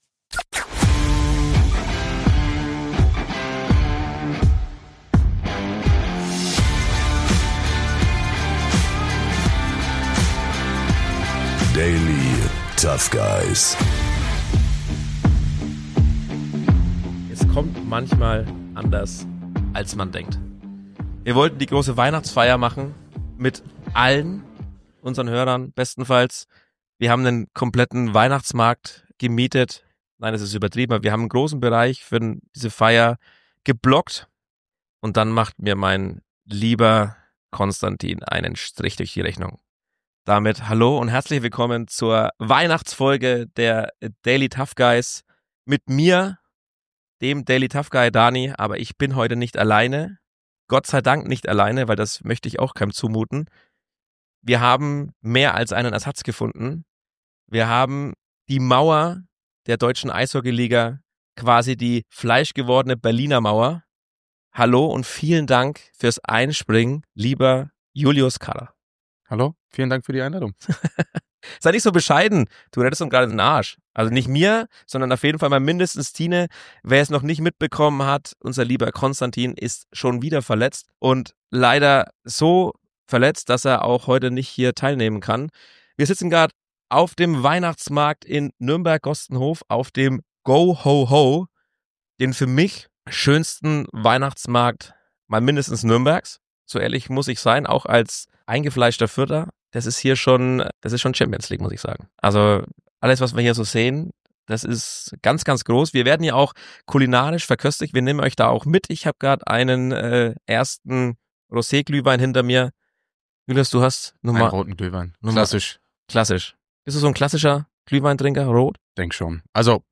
Live vom GoHo ~ Daily Tough Guys Podcast